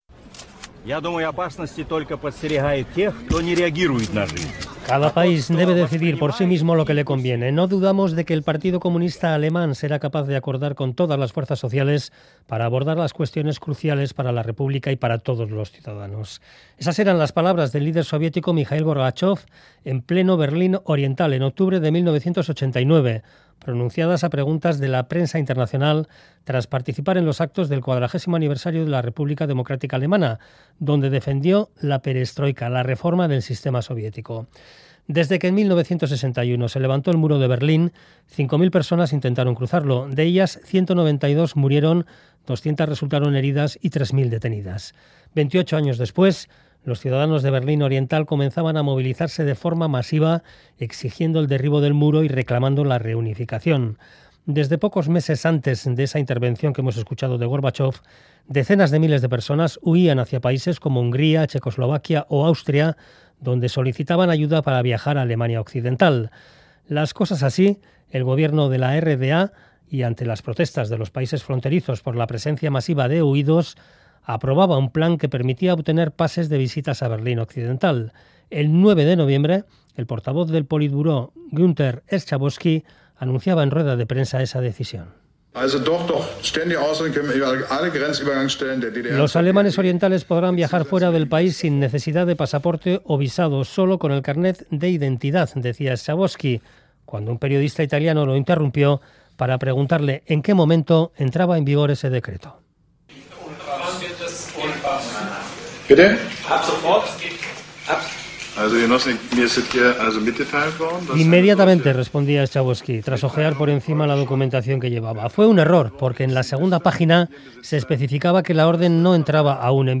Audio: Reportaje sobre la caída del Muro de Berlín y balance de estos 30 años desde un punto de vista económico, institucional y geopolítico.